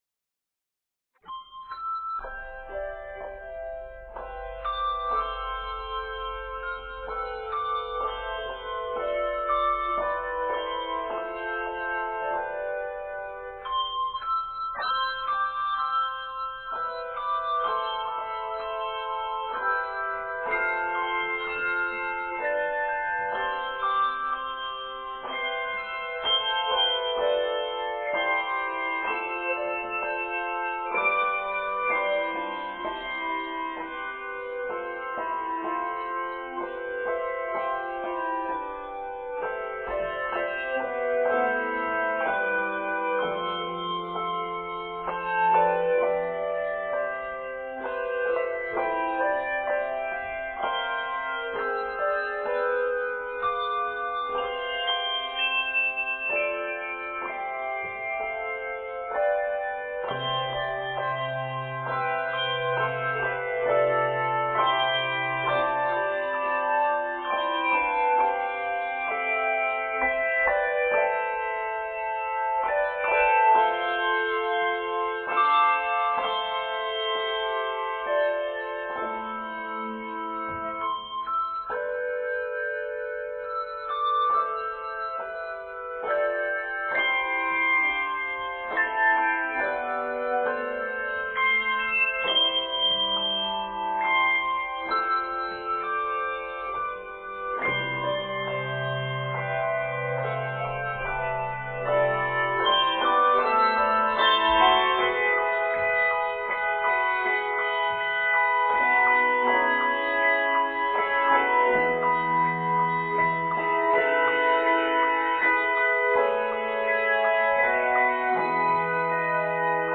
transcribed for handbells
fresh harmonic surprises